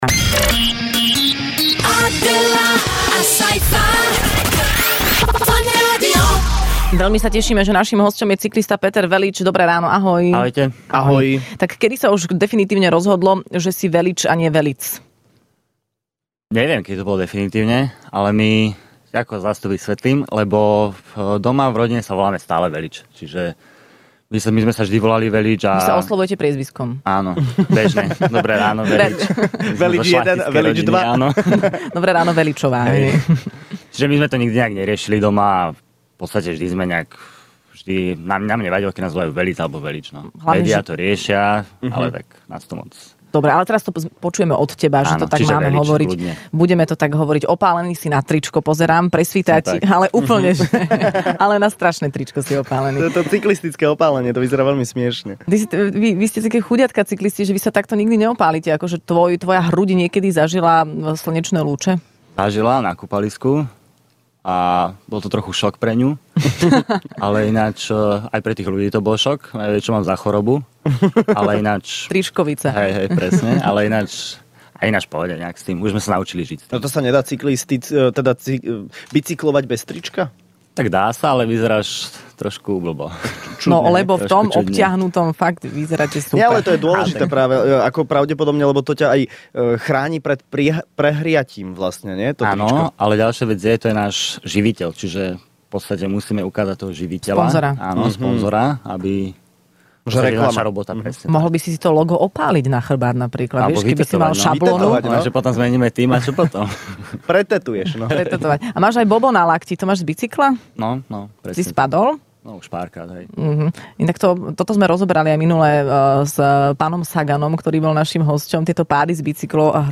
Hosťom v Rannej šou bol cyklista Peter Velits, ktorý sa vrátil z legendárnej Tour de France, kde sa umiestnil na celkovo vynikajúcom 19. mieste.